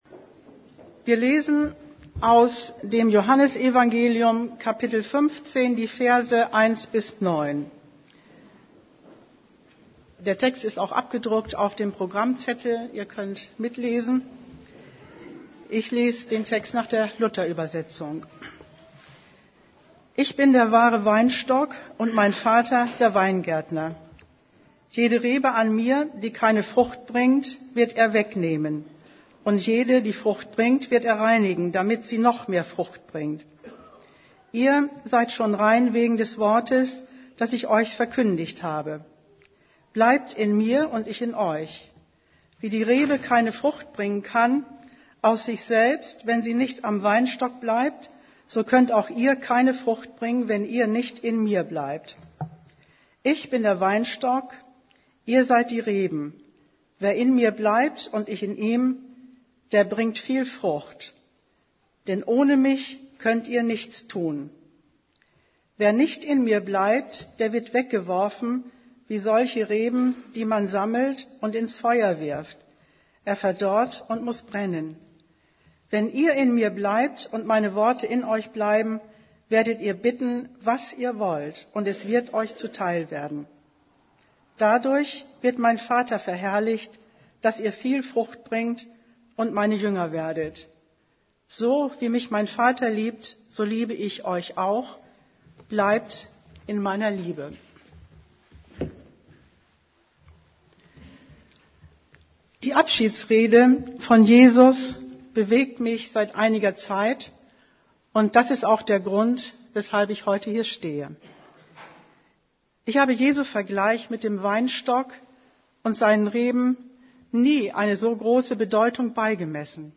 Predigt vom 05.